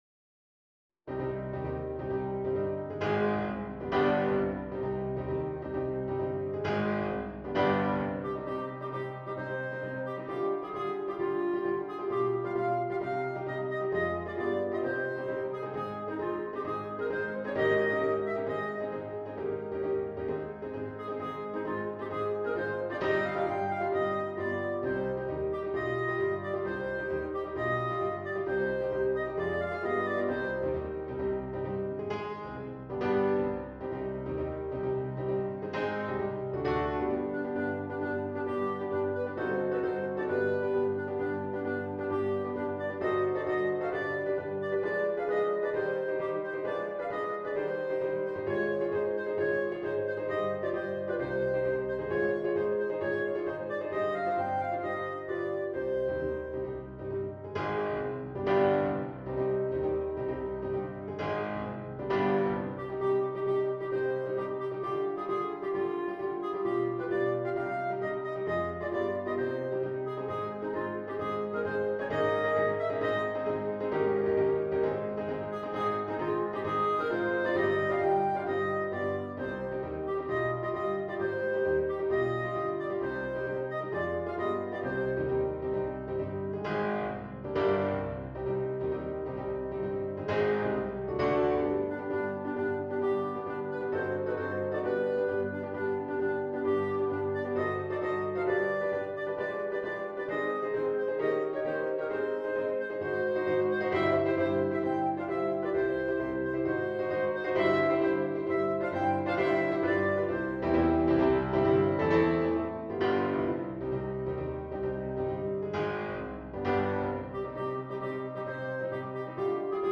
This file contains the performance, accompaniment, and sheet music for Bb Clarinet.